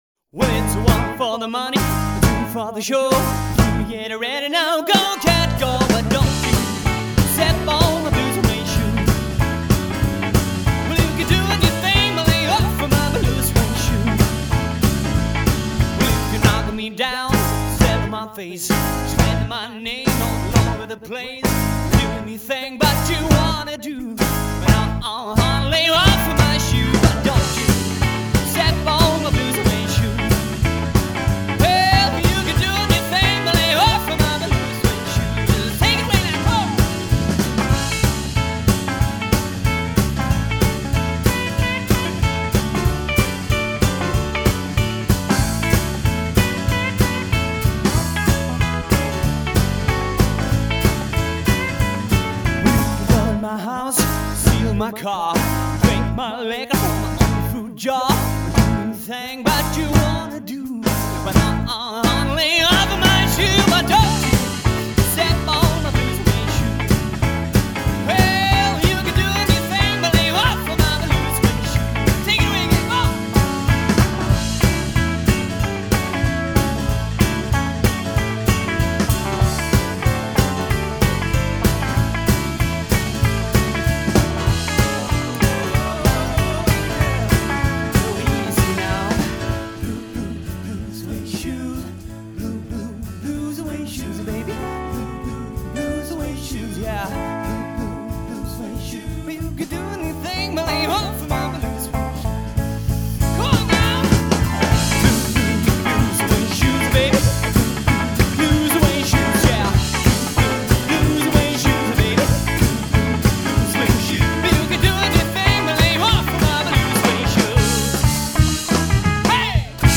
sång, piano.
gitarr.
bas.
trummor.